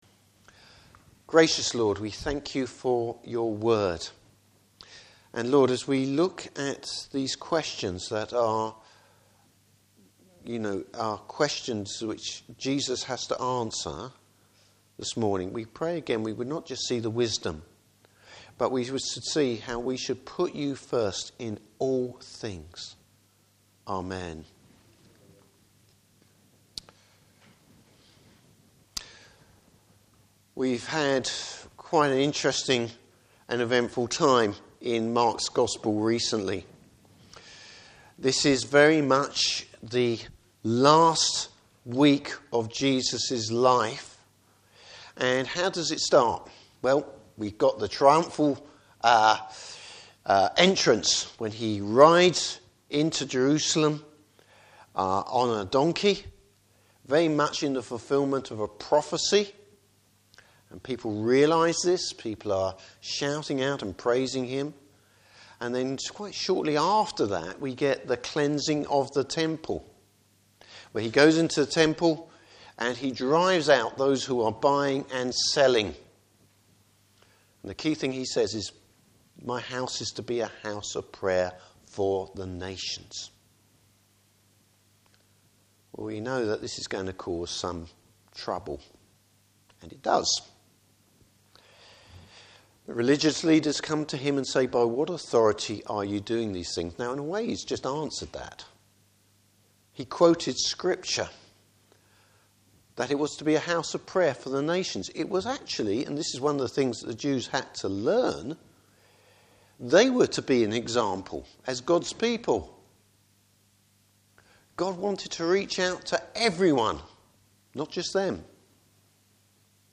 Service Type: Morning Service Jesus teaches where true authority derives from.